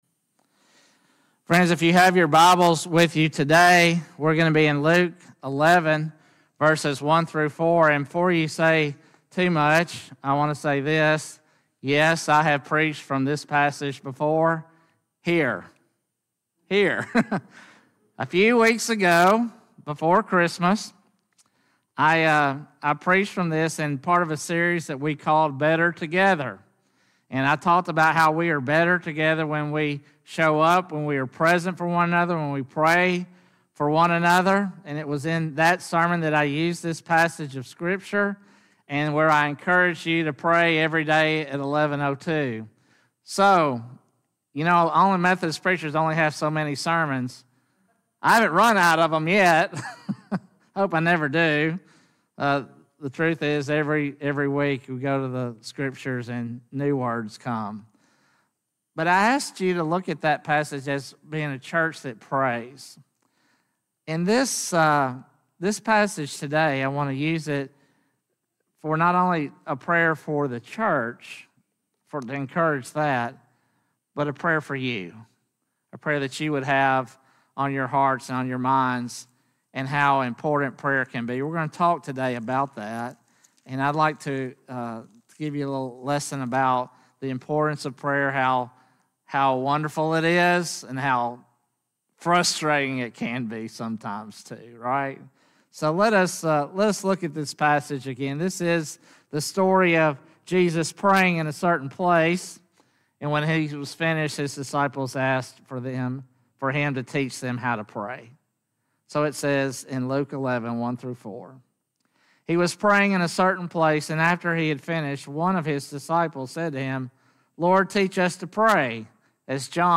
Sermons | First United Methodist Church